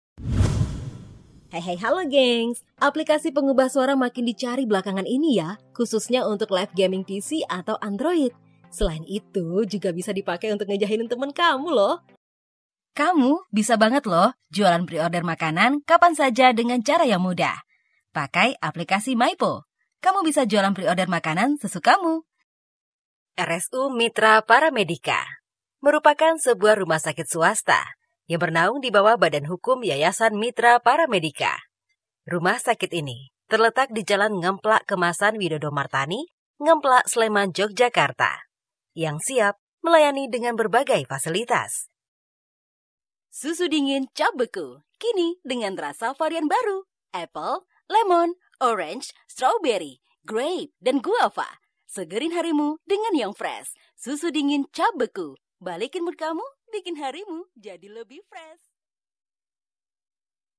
Indonesian Female No.1
Radio Advertising